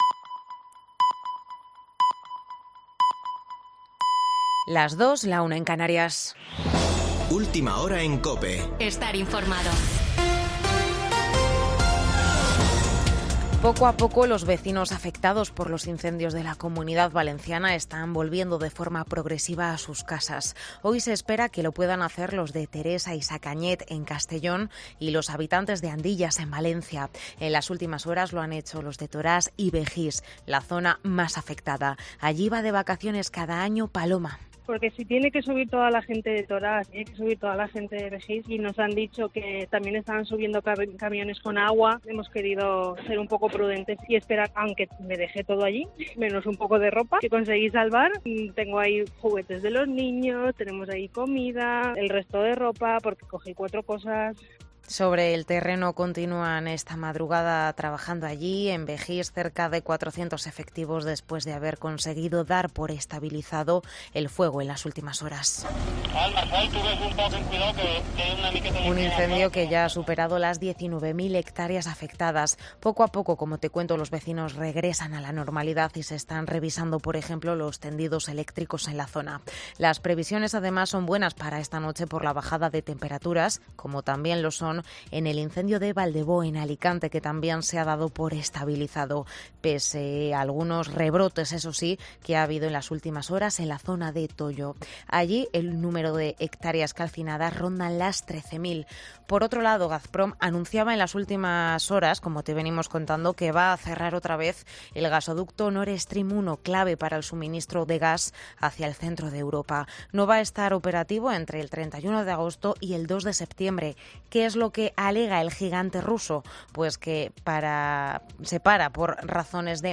Boletín de noticias de COPE del 21 de agosto de 2022 a las 02.00 horas